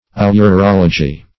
ourology - definition of ourology - synonyms, pronunciation, spelling from Free Dictionary Search Result for " ourology" : The Collaborative International Dictionary of English v.0.48: Ourology \Ou*rol"o*gy\, n. See Urology .